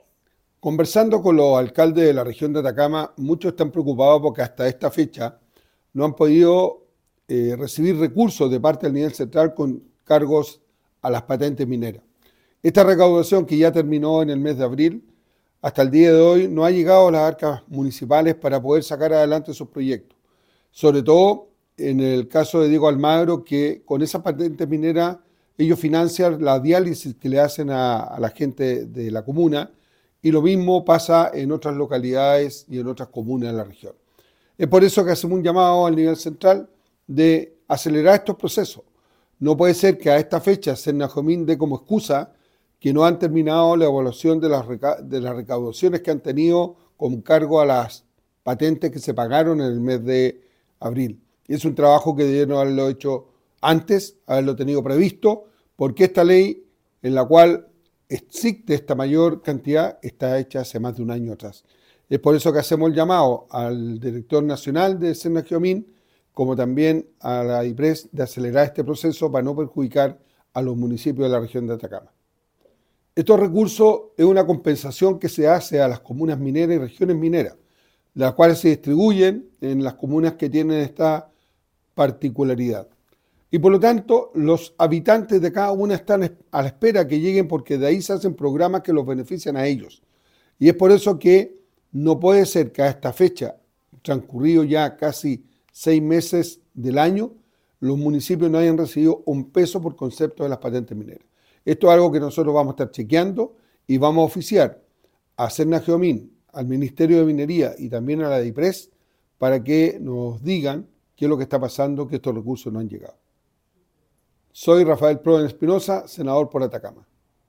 Senador-Prohens-se-refiere-al-pago-de-patentes-mineras-a-municipios.mp3